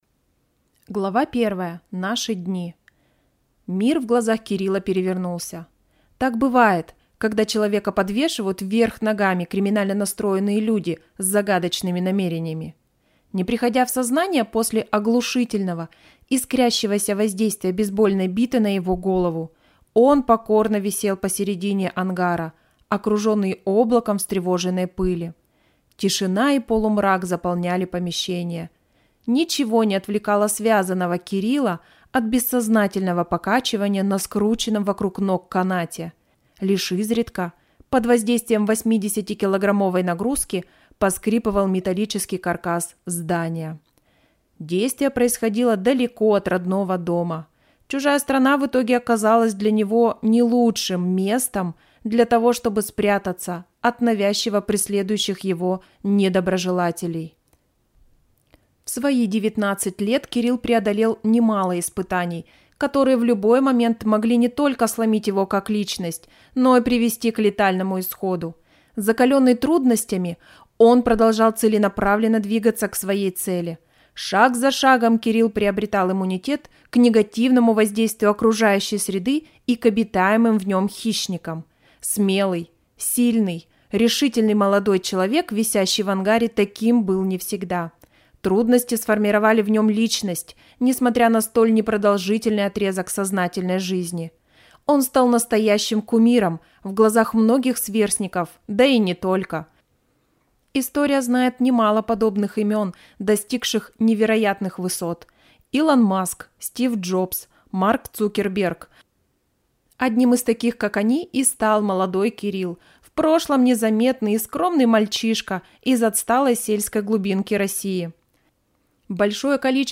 Аудиокнига Солнечный зайчик | Библиотека аудиокниг